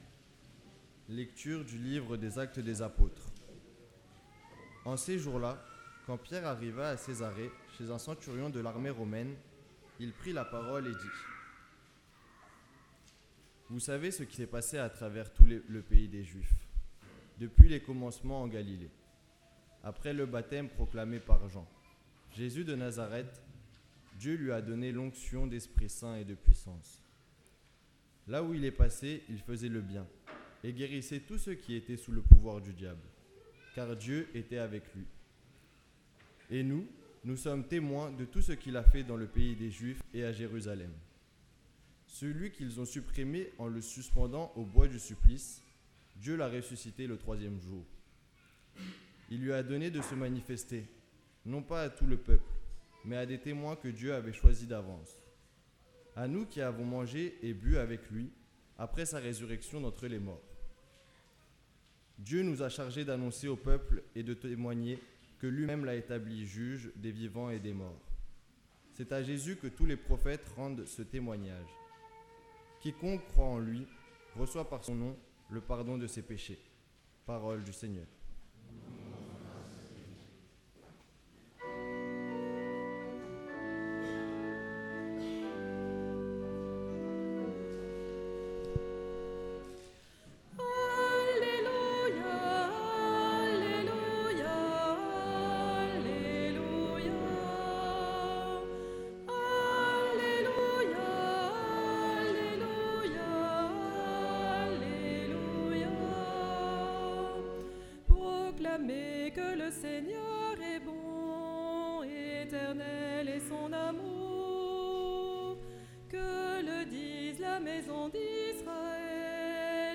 Parole de Dieu & Sermon du 9 Avril 2023, Dimanche de Pâques